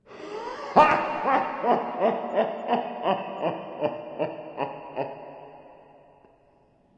На этой странице собраны звуки безумия: маниакальный смех, невнятные крики, стоны и другие проявления психоза.
Зловещий звук смеха опасного существа